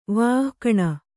♪ vāhkaṇa